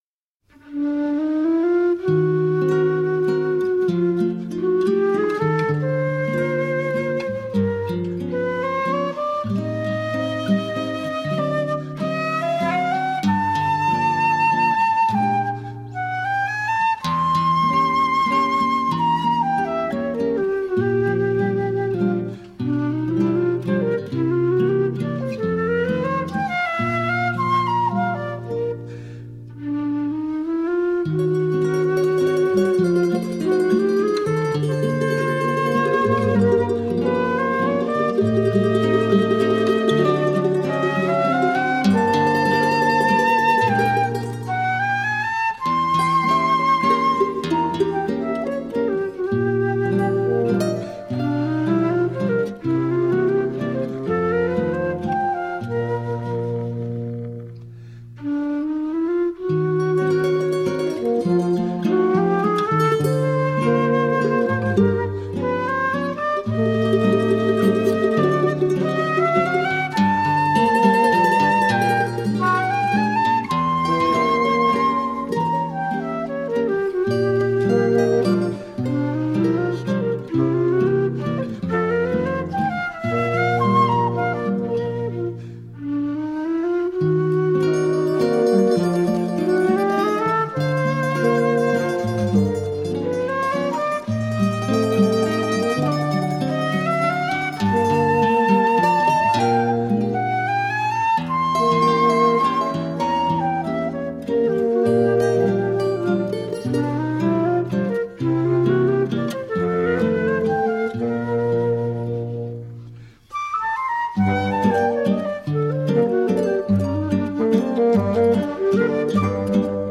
Brazilian contemporary and traditional flute.
with flute, acoustic guitar, fagot and percussion